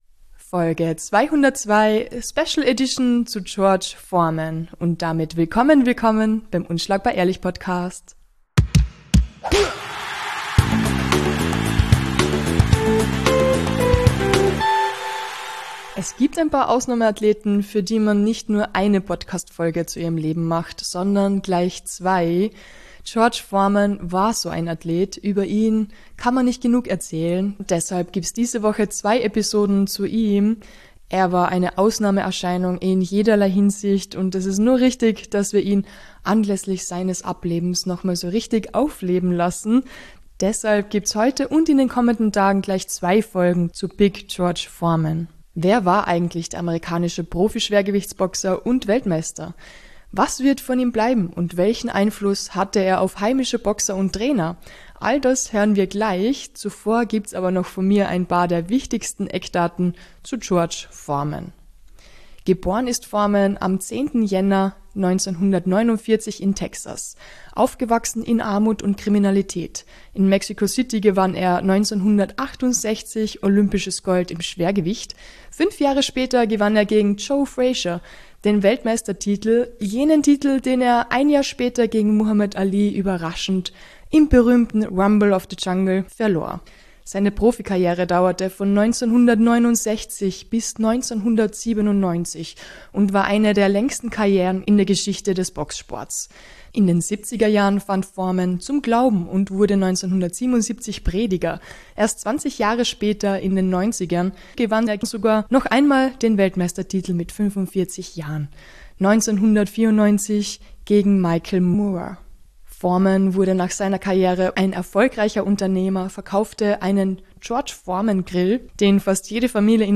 Neun Box-Experten geben Antworten auf diese Fragen und erzählen uns von ihren Erinnerungen an die verstorbene Box-Legende.